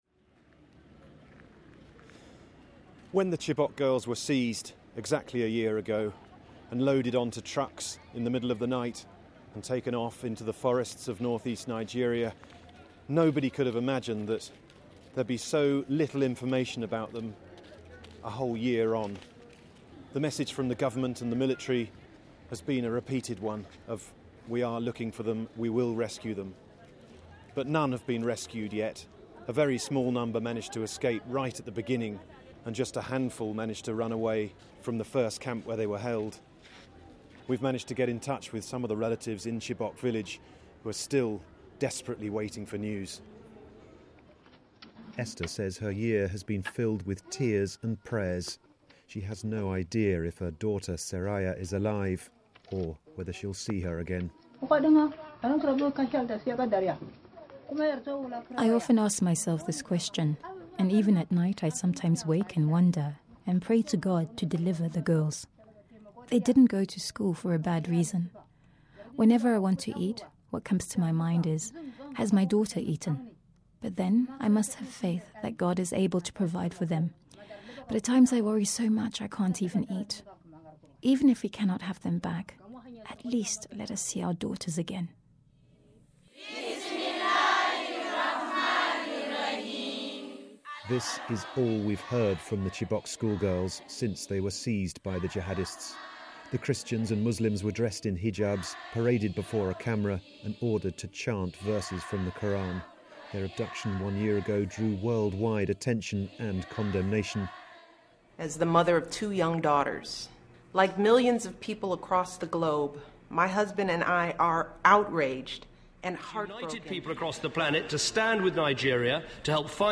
President Goodluck Jonathan on Chibok - interviewed before the election